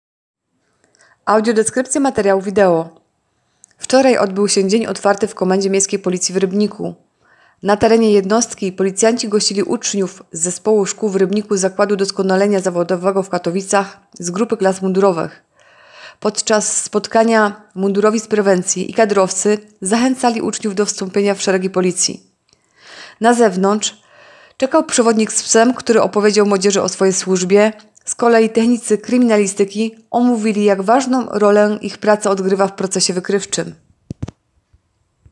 Opis nagrania: Audiodeskrypcja.